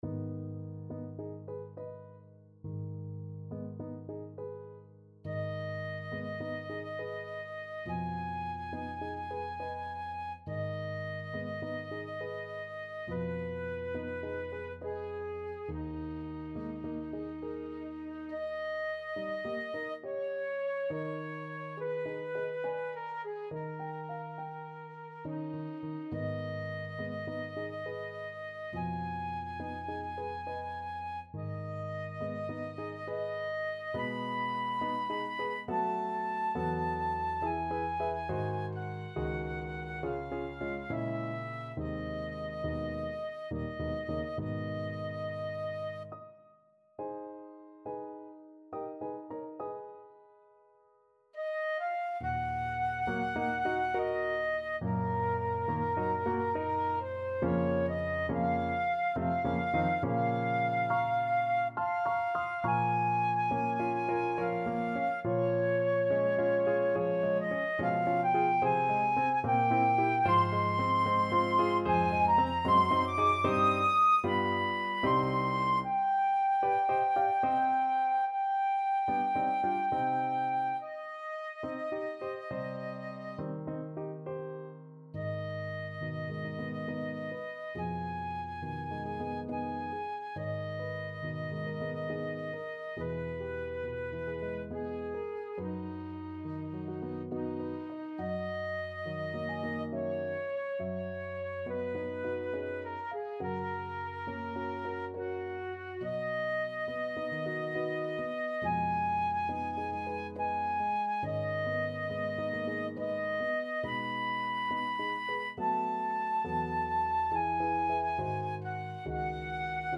Andante =69
3/8 (View more 3/8 Music)
Flute  (View more Intermediate Flute Music)
Classical (View more Classical Flute Music)